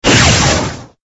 lightning_1.ogg